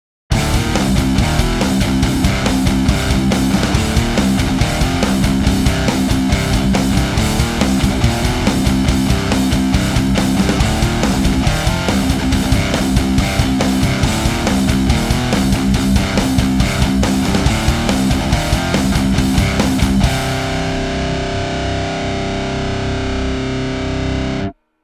METAL Gojir 2.mp3